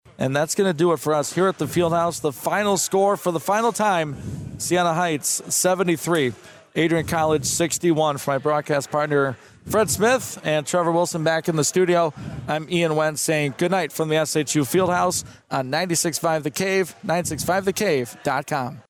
96.5 The Cave carried the games on the airwaves.